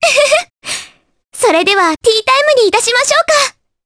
Mirianne-Vox_Victory_jp.wav